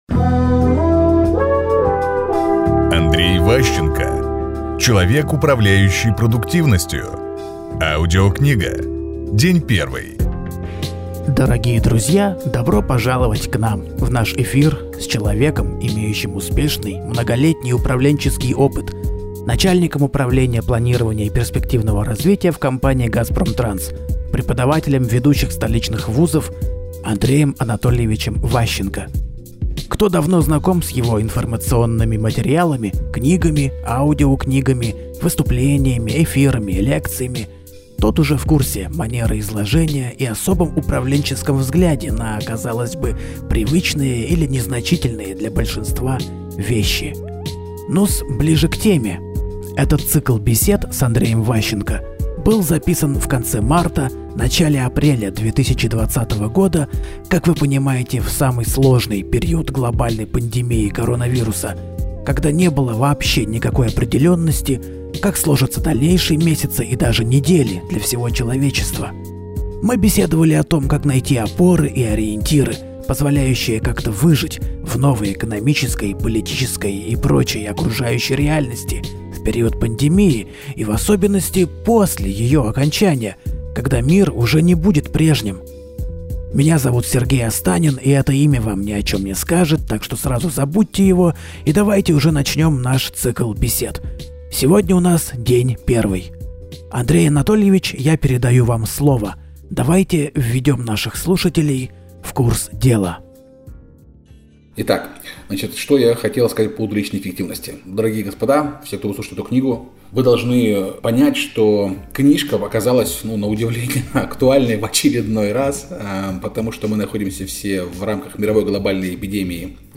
Аудиокнига Человек, управляющий продуктивностью. Часть 1 | Библиотека аудиокниг